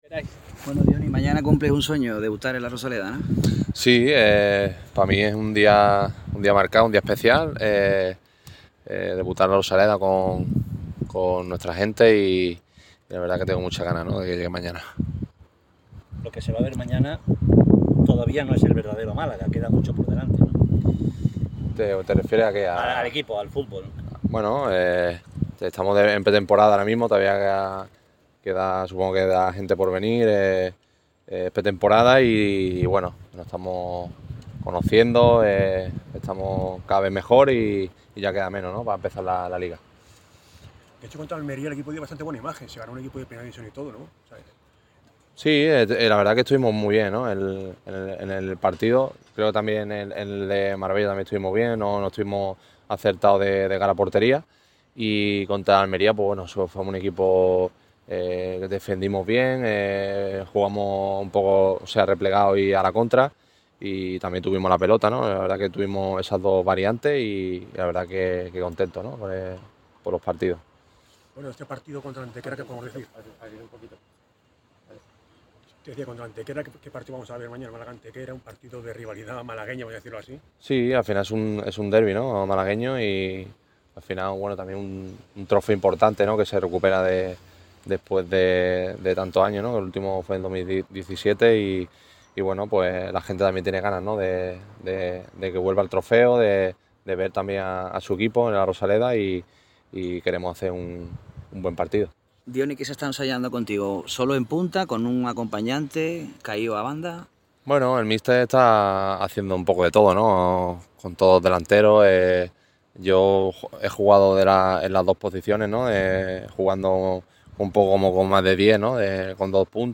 Este viernes se ha presentado ante los medios el XXXIV Trofeo Costa del Sol. Ha tenido lugar en el césped de La Rosaleda